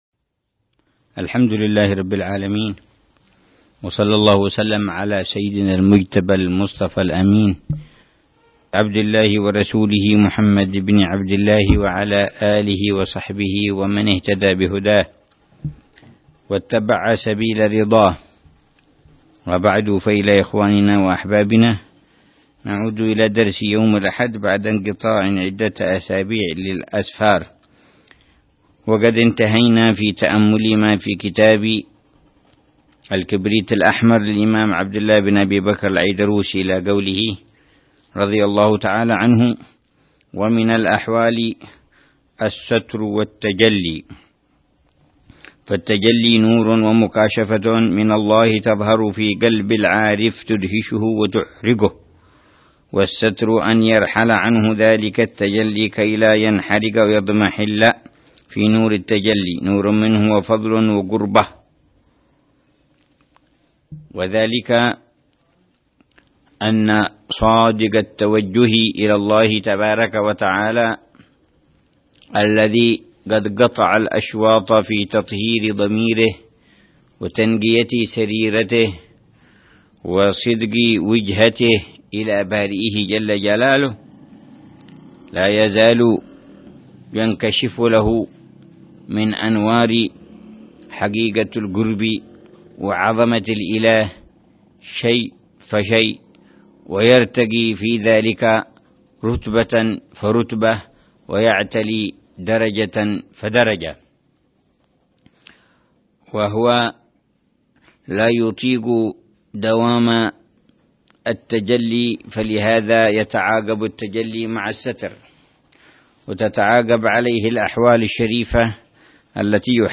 درس أسبوعي يلقيه الحبيب عمر بن حفيظ في كتاب الكبريت الأحمر للإمام عبد الله بن أبي بكر العيدروس يتحدث عن مسائل مهمة في تزكية النفس وإصلاح القلب